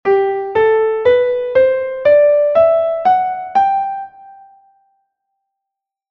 escalasol.mp3